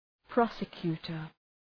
Προφορά
{‘prɒsə,kju:tər} (Ουσιαστικό) ● κατήγορος ● εισαγγελεύς ● μηνυτής